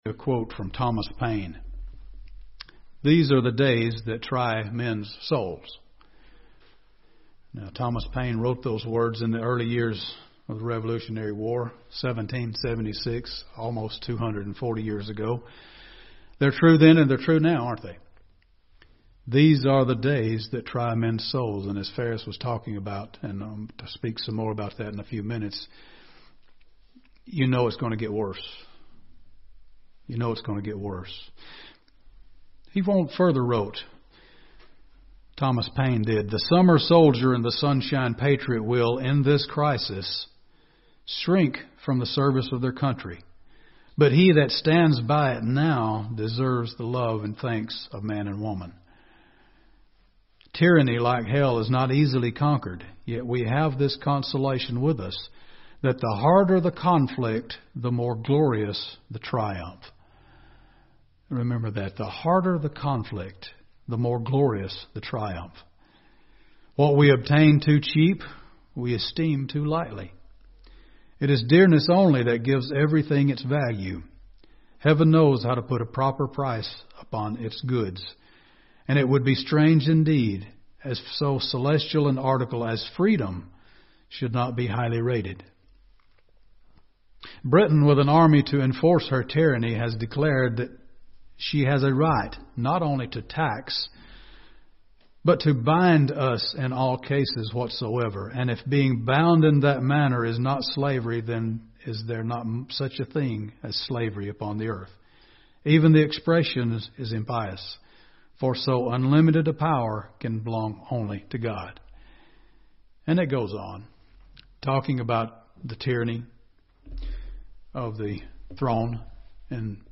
Given in Gadsden, AL
UCG Sermon Studying the bible?